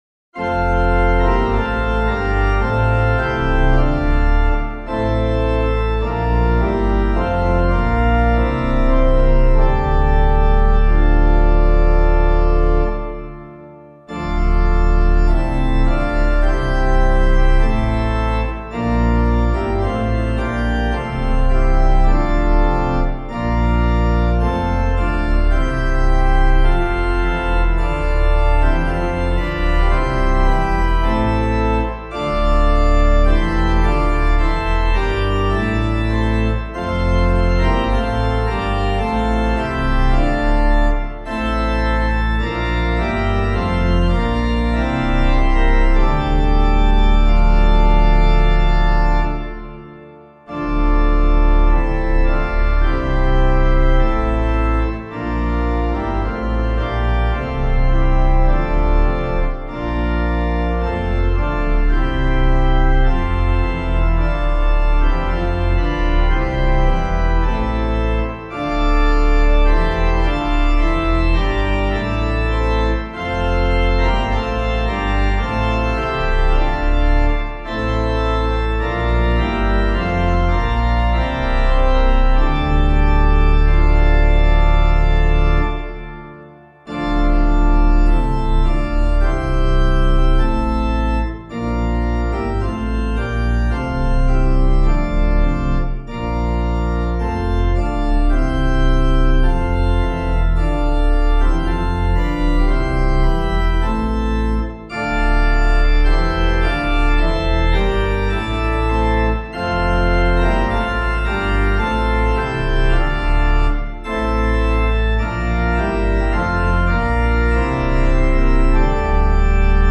Composer:    French Church melody from Poitier Antiphoner, 1746.